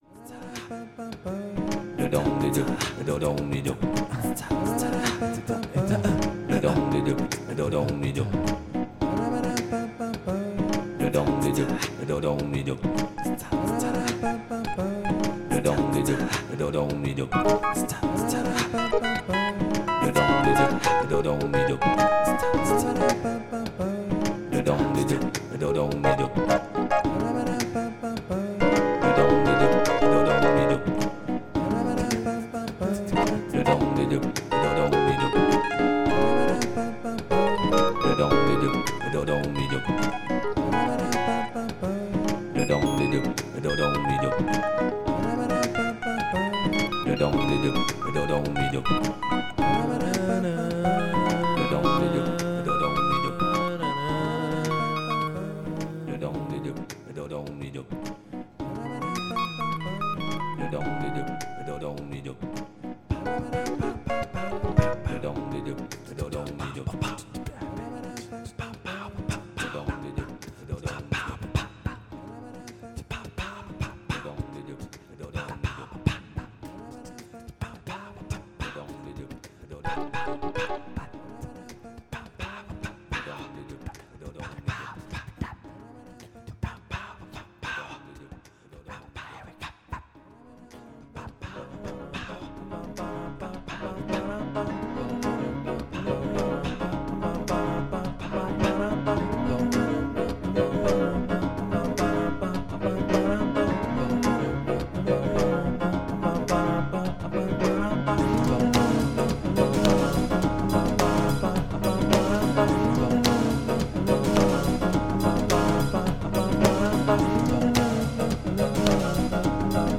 Spontaneous, raw, music from the heart ---